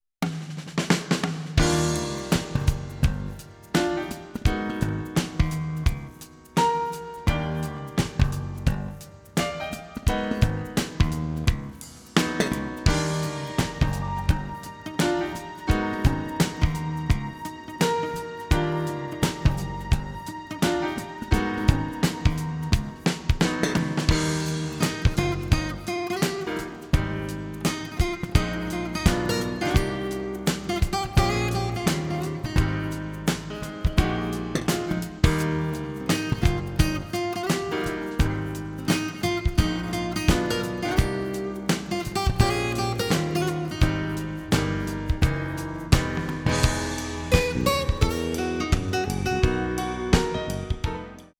Håll i er, det är mässjazz!
Båda LFOerna styr pitch
Edit: Dom första 15 sekundrarna går opåverkade.
På 16Hz exempelt är det ju på gitarren man främst hör det.